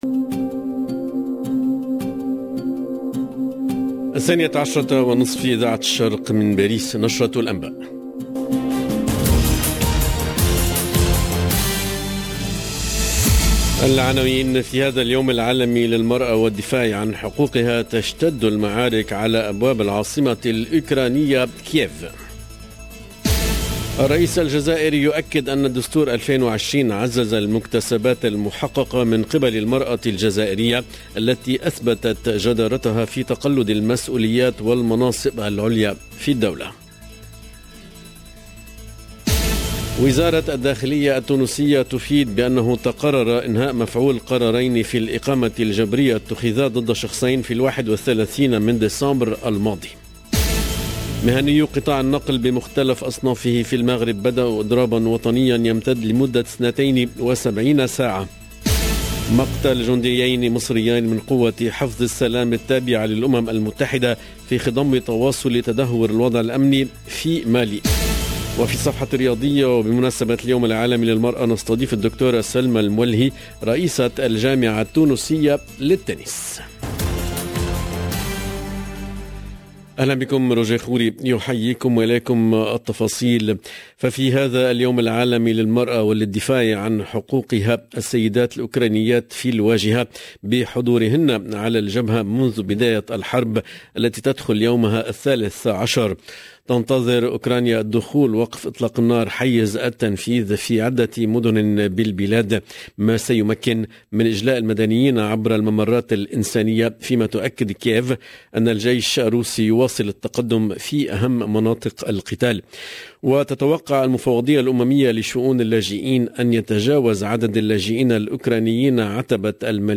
LE JOURNAL DE MIDI 30 EN LANGUE ARABE DU 8/03/22